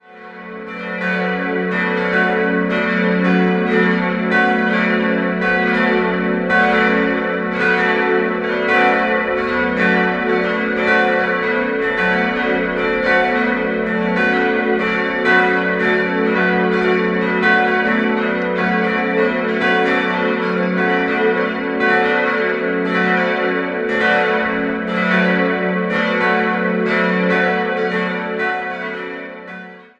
4-stimmiges Geläute: fis'-a'-h'-cis'' Alle Glocken stammen aus der Gießerei Friedrich Wilhelm Schilling und wurden 1957 gegossen.